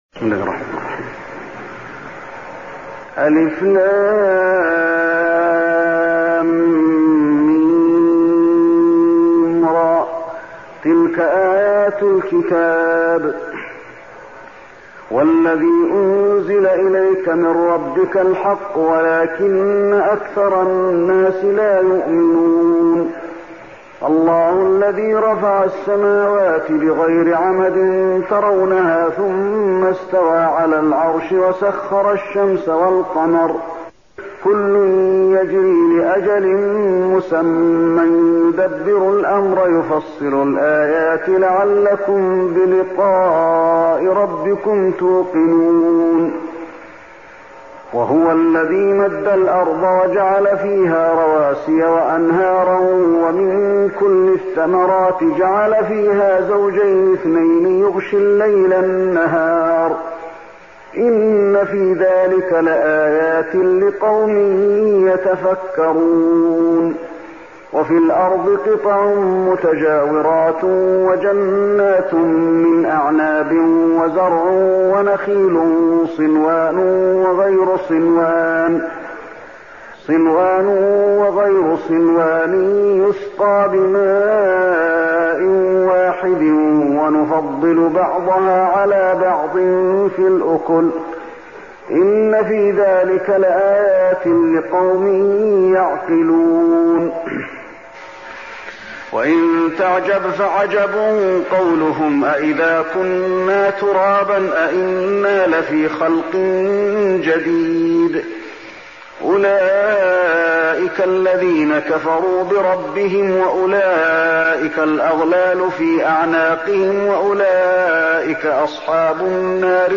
المكان: المسجد النبوي الرعد